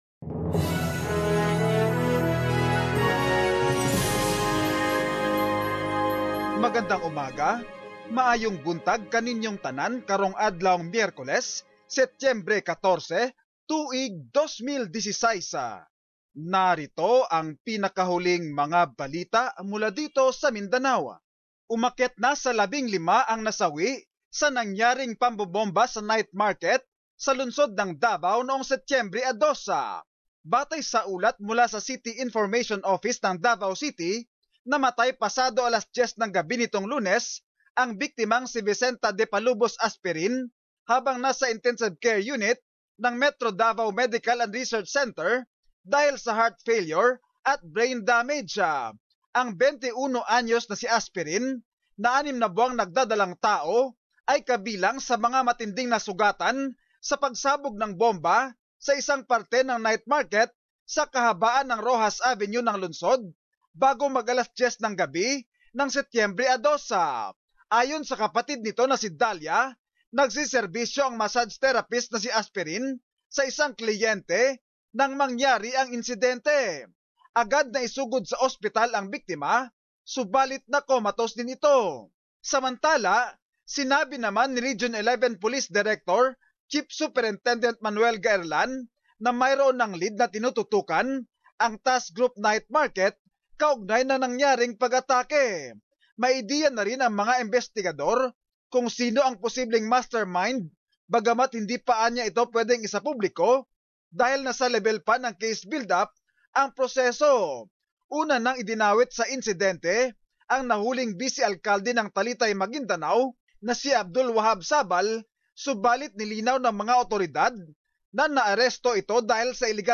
Mindanao News: Summary of latest news from the region